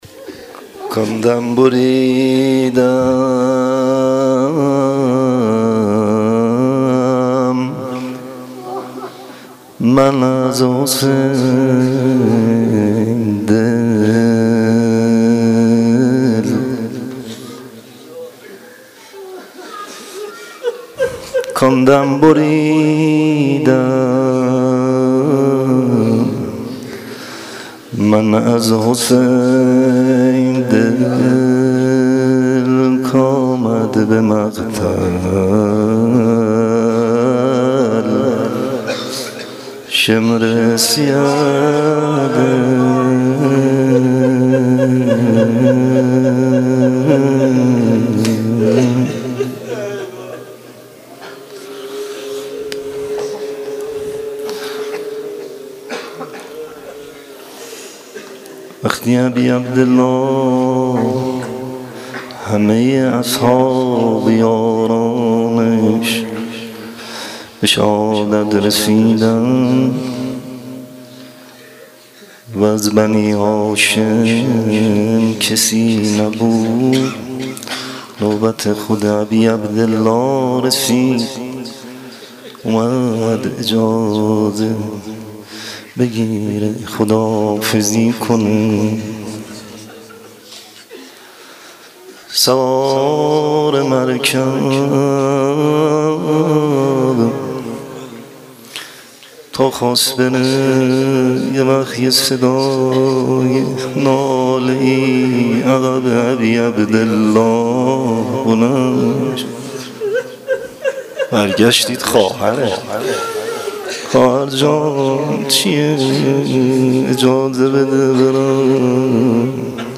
روضه - شام غریبان حسینی